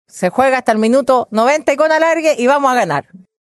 En la misma línea, la presidenta del Partido Socialista y jefa de campaña de la candidata, Paulina Vodanovic, afirmó que los comicios “se juegan hasta el minuto 90…”.